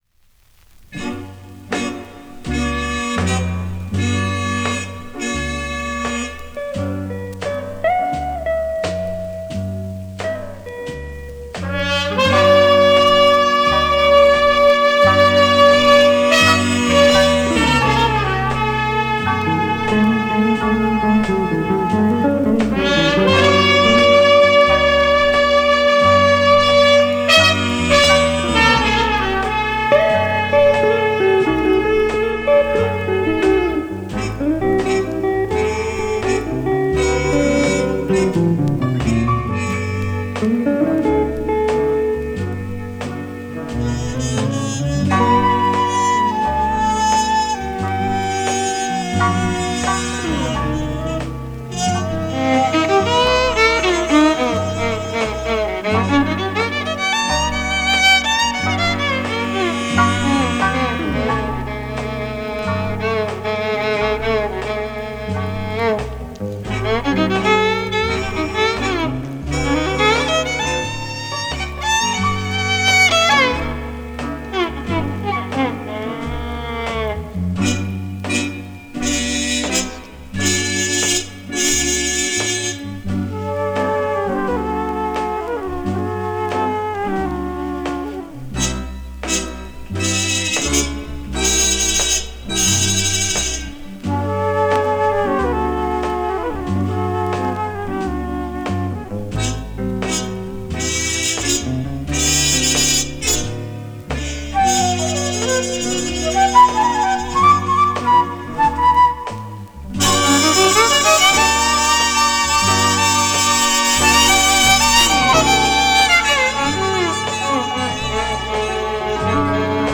blues single